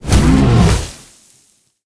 File:Sfx creature pinnacarid death land 01.ogg - Subnautica Wiki
Sfx_creature_pinnacarid_death_land_01.ogg